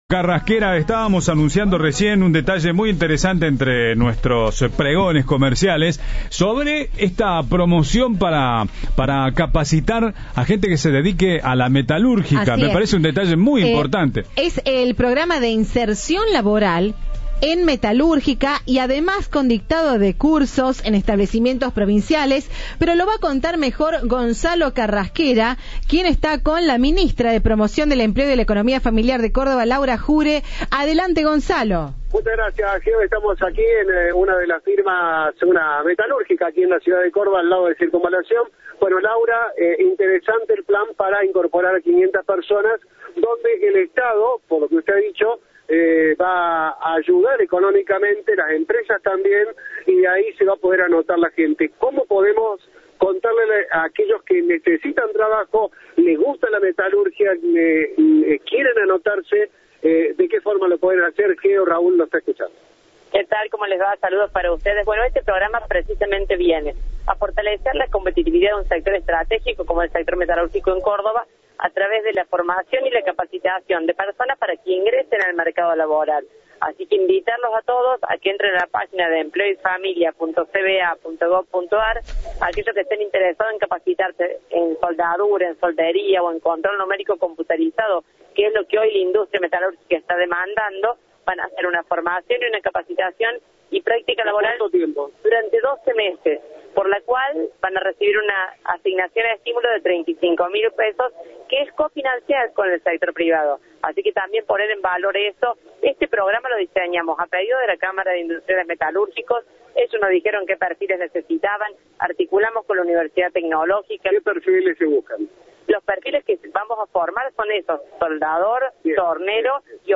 La Ministra de Promoción del Empleo y de la Economía Familiar, Laura Jure, habló con Cadena 3 sobre el Programa de Inserción Laboral (PIL), que generará 500 posibilidades de empleo en la industria metalúrgica.